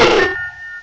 pokeemmo / sound / direct_sound_samples / cries / shieldon.aif